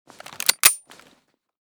pistol_unjam.ogg